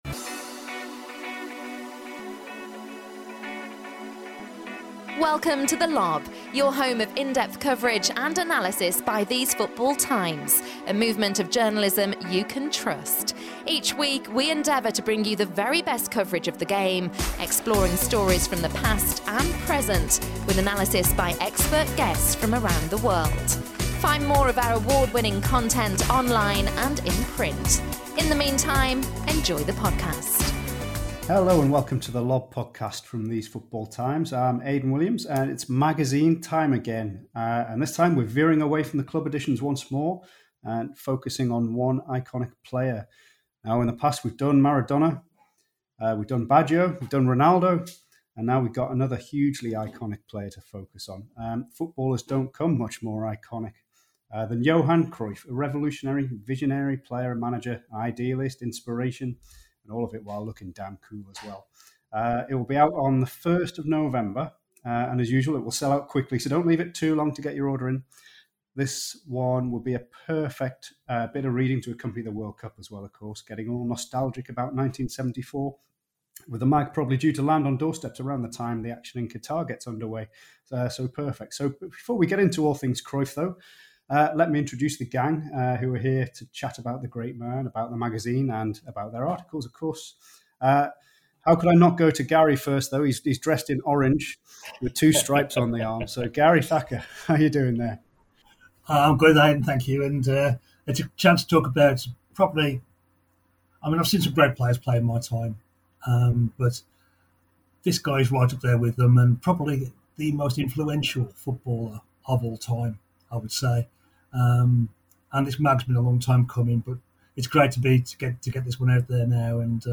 To celebrate the launch of the Johan Cruyff magazine, the podcast team got together to discuss their memories of the great man. From watching him in the flesh to his impact and legacy as a player and manager, this is 90 minutes on all things Cruyff.